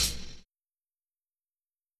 Closed Hats
Mac x Earl Hat.wav